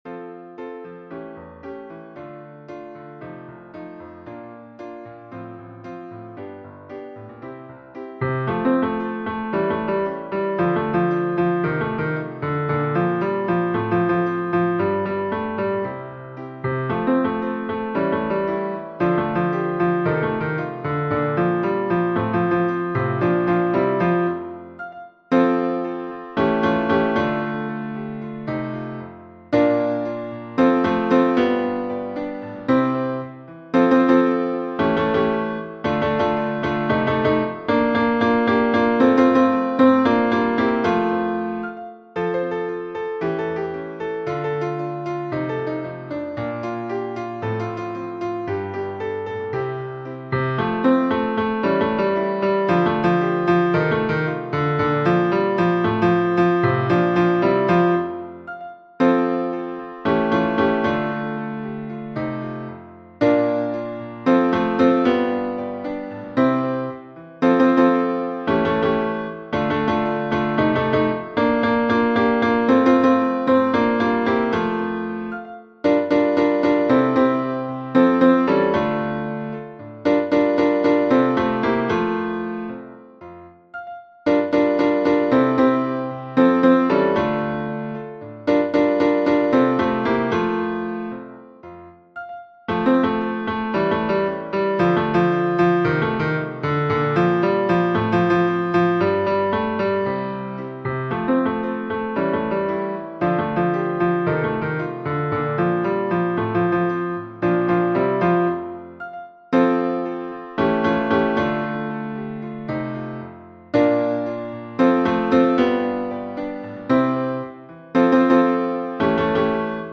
MP3 version piano
Tenor